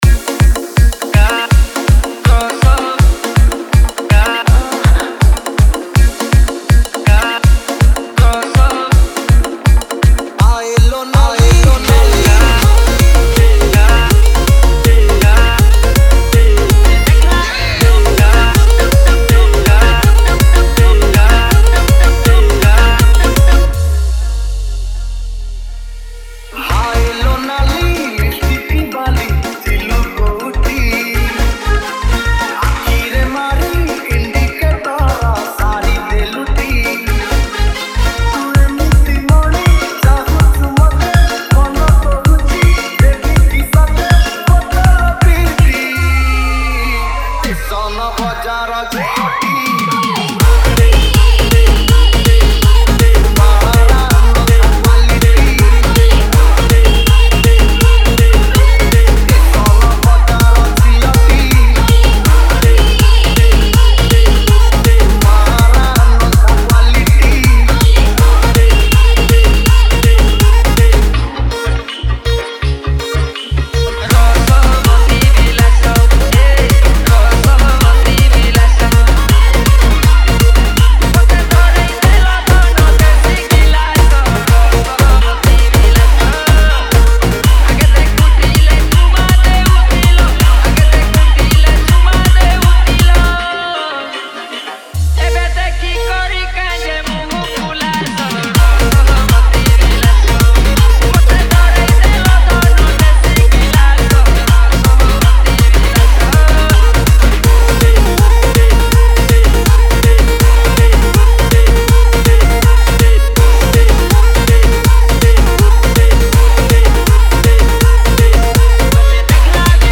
MASHUP 2022 Songs Download